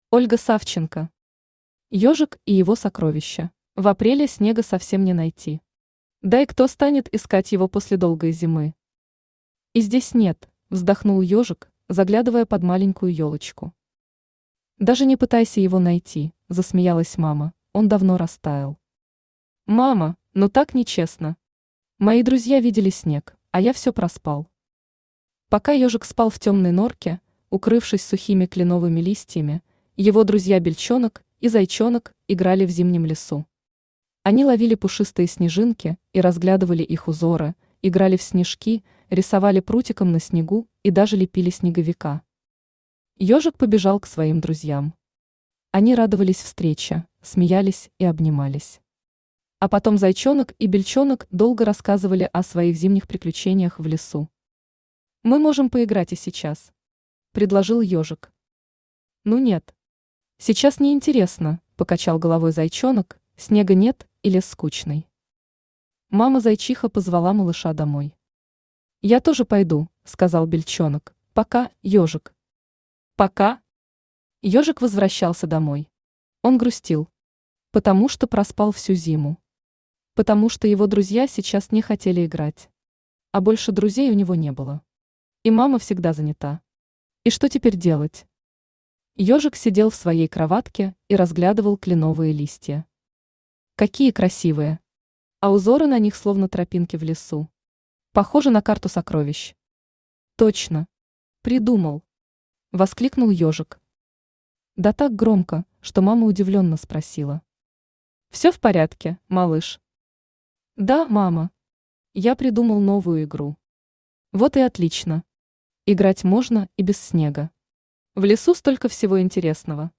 Аудиокнига Ёжик и его сокровище | Библиотека аудиокниг
Aудиокнига Ёжик и его сокровище Автор Ольга Савченко Читает аудиокнигу Авточтец ЛитРес.